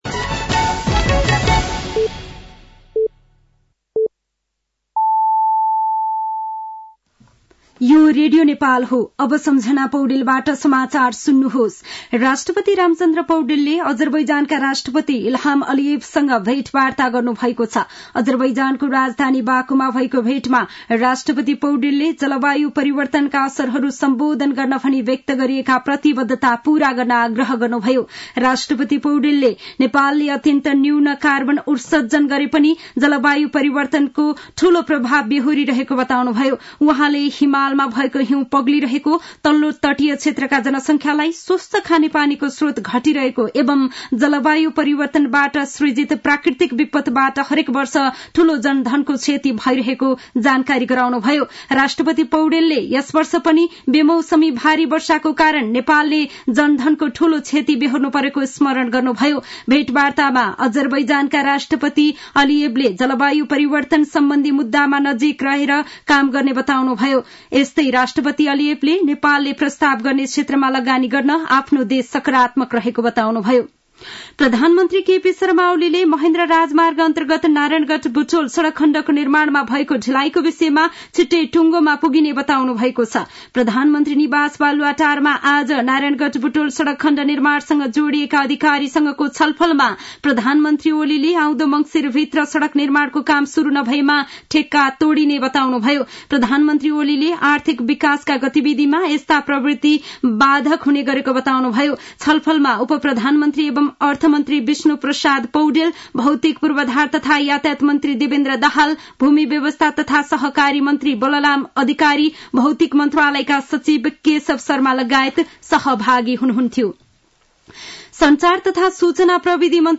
साँझ ५ बजेको नेपाली समाचार : ३० कार्तिक , २०८१
5-pm-nepali-news-7-29.mp3